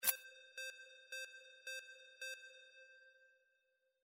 Stereo sound effect - Wav.16 bit/44.1 KHz and Mp3 128 Kbps